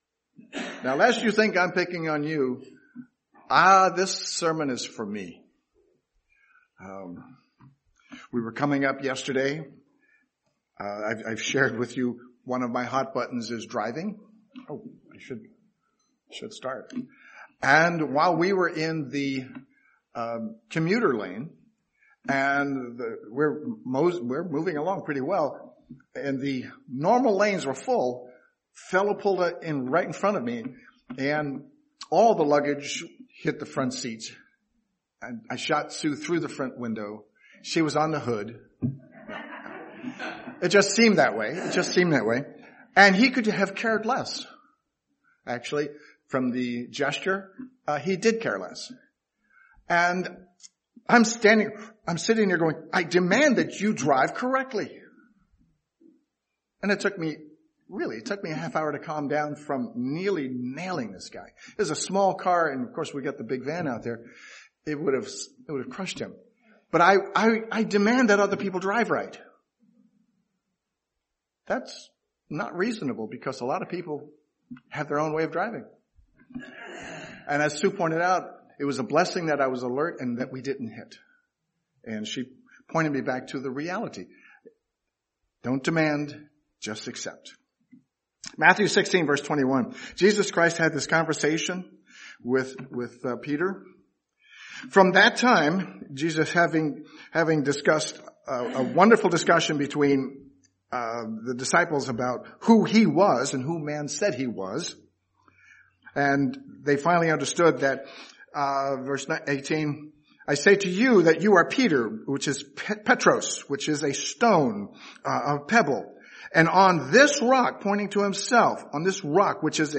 Sermons
Given in San Jose, CA